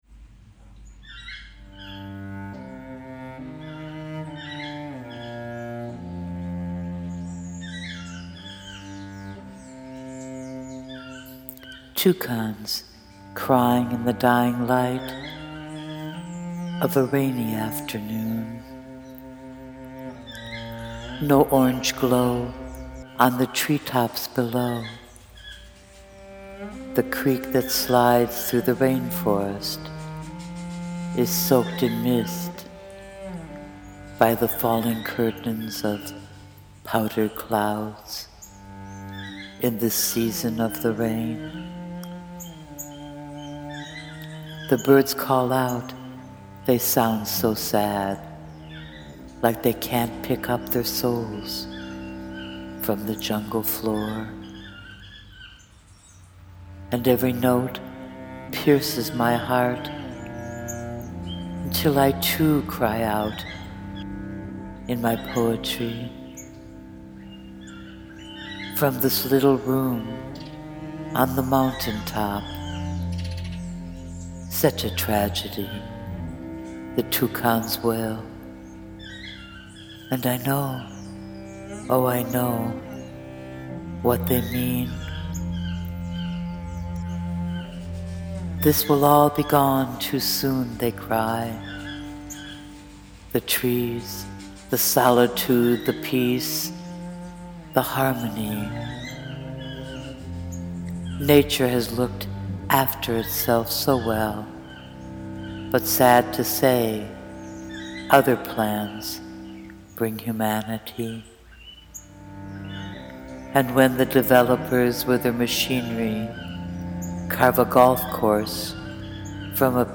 Beautifully written, beautifully read.
toucans.mp3